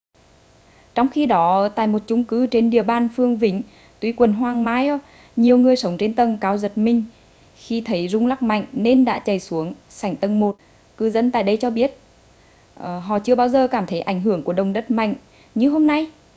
Text-to-Speech
female